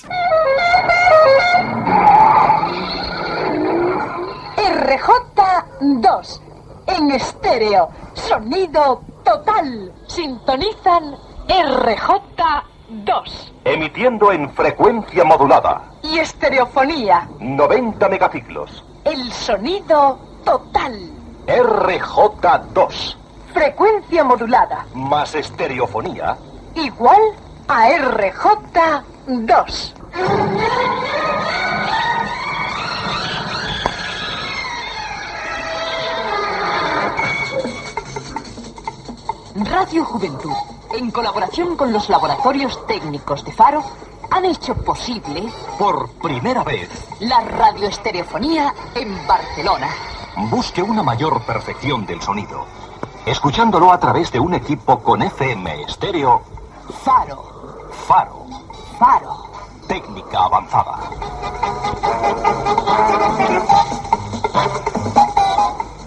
Promoció de l'emissió en estereofonia de RJ2, novetat en aquell moment.
FM